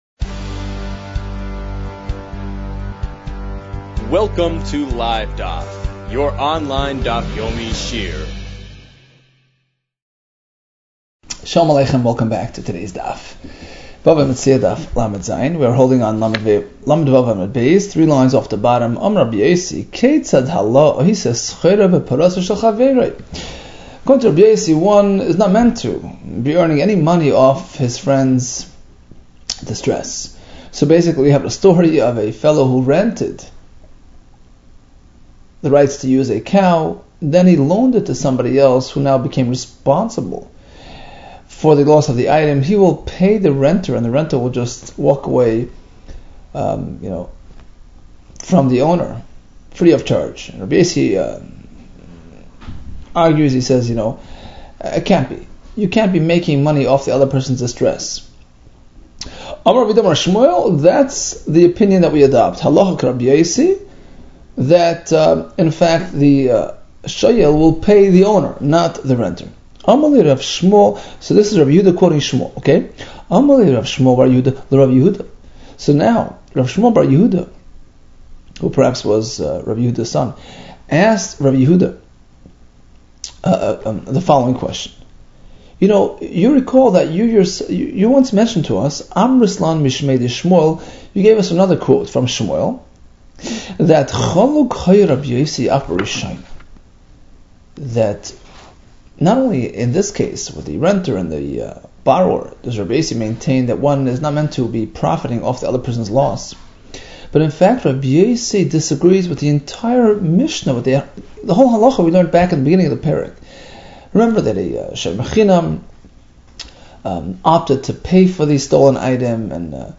Bava Metzia 36 - בבא מציעא לו | Daf Yomi Online Shiur | Livedaf